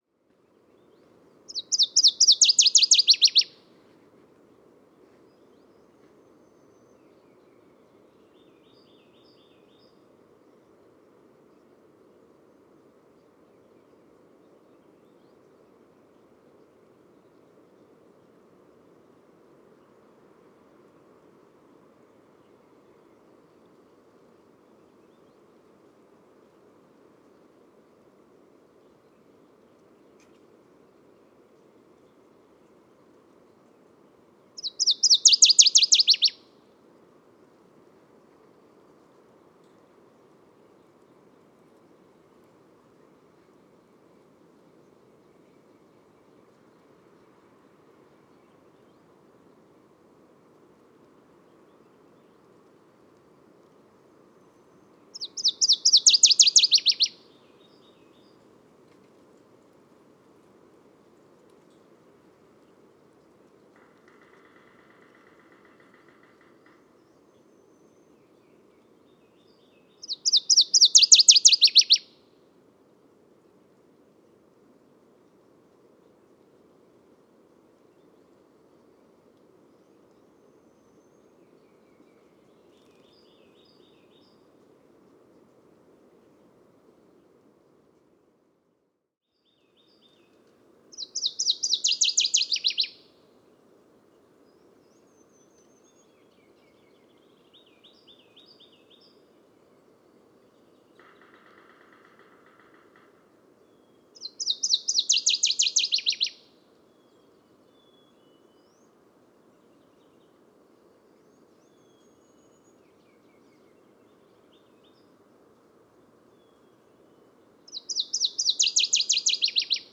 Paruline des ruisseaux – Parkesia noveboracensis
Chant Réserve faunique Duchénier, QC. 17 mai 2019. 11h15.